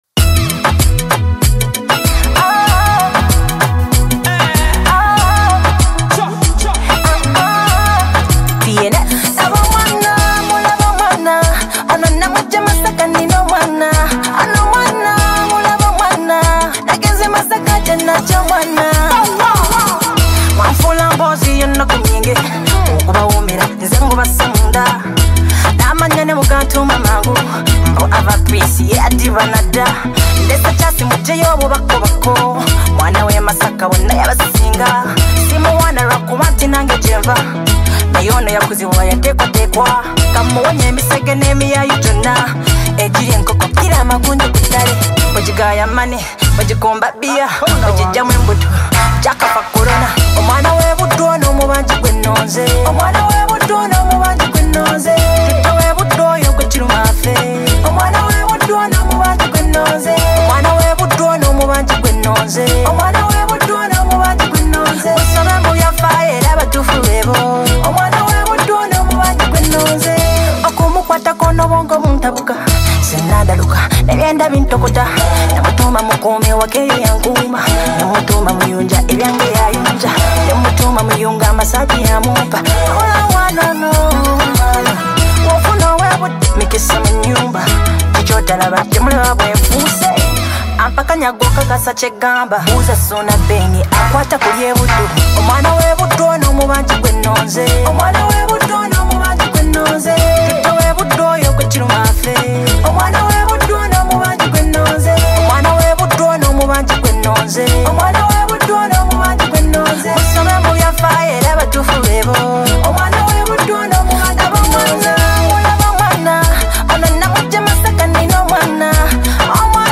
energetic track
Genre: Afrobeat